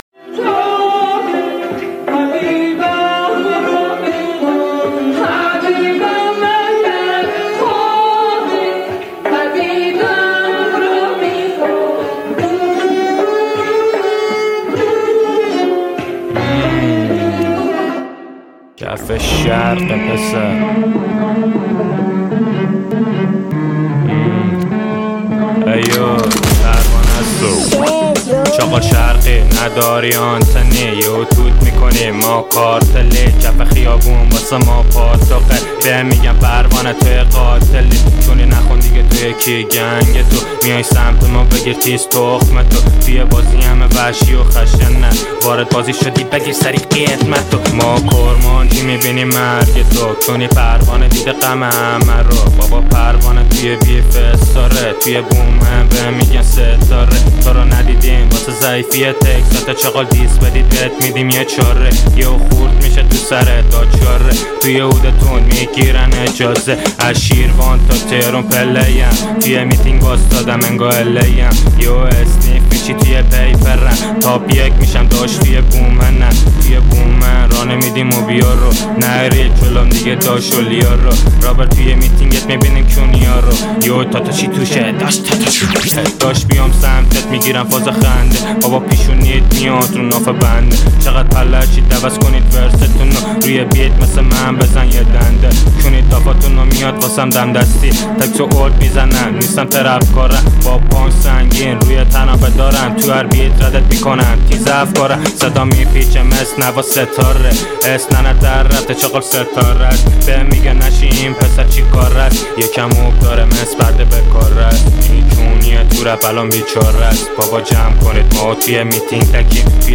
موزیک سومم که مثلا گنگه (من زیاد بلد نیستم گنگ بخونم و بنویسم)
(Rapper)